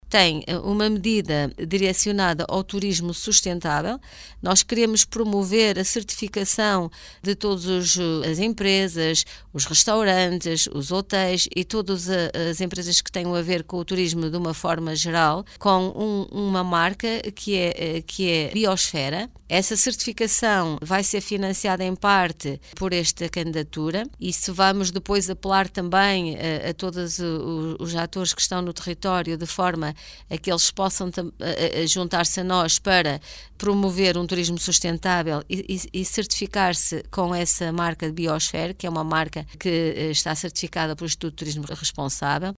De entre as três medidas previstas, uma está direcionada à certificação de qualidade do turismo sustentável na região, como explica a presidente do Zasnet Berta Nunes: